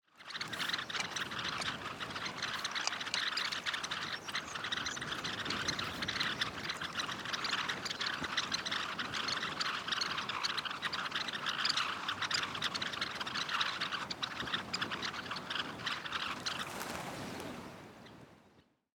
Голос тихий, щебечущий.